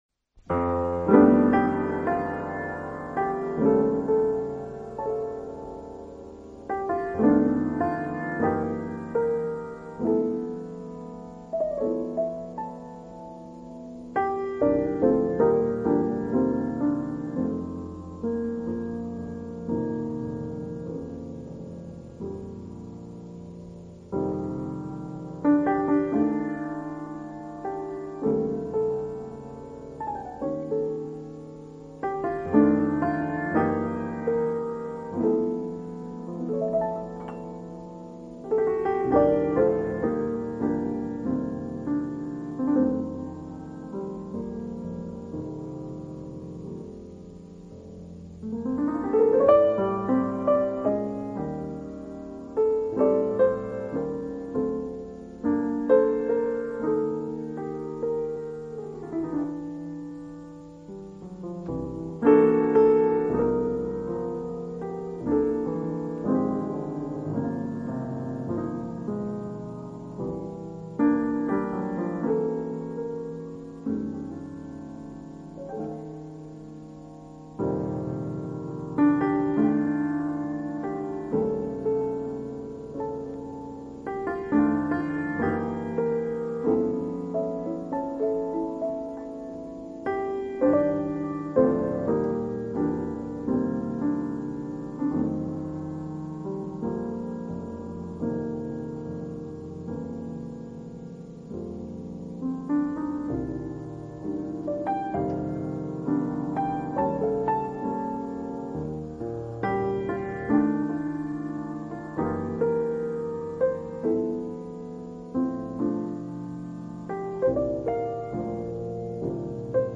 and audio in Bb